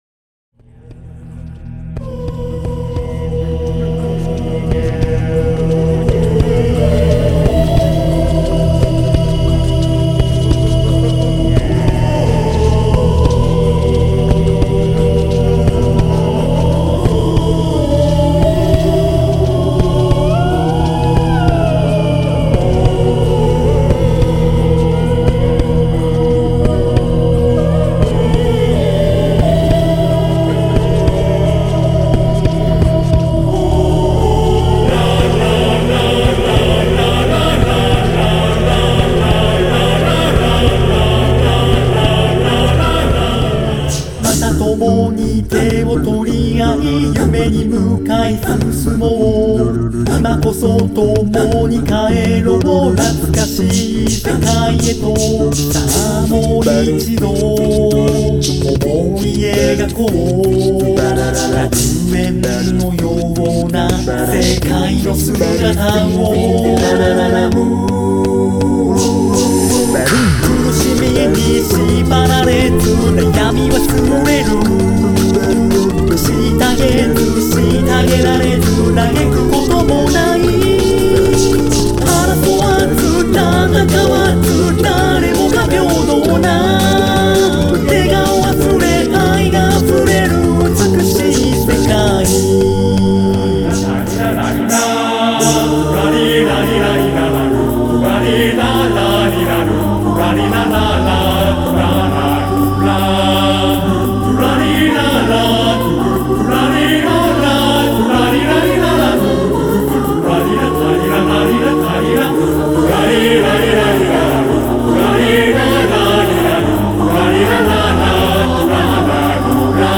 このアルバムは、一人の声を重ねに重ねた音だけで作られた曲で構成されています。
いわゆるボイパをはじめとするアカペラの要素や、数多くの声を重ねることによる合唱の要素を取り入れた
クロスフェードデモ